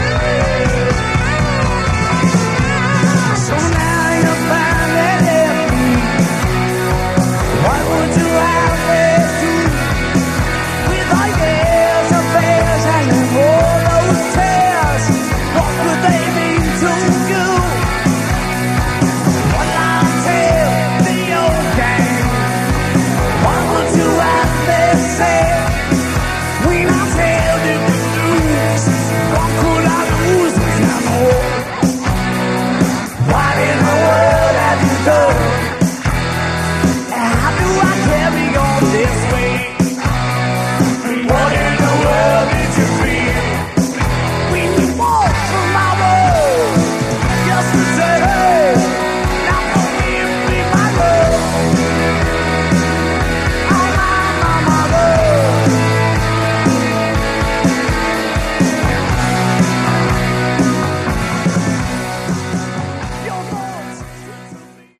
Category: Hard Rock
guitars, keyboards
drums, percussion
live bonus track